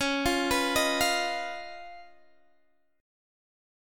Db9 Chord
Listen to Db9 strummed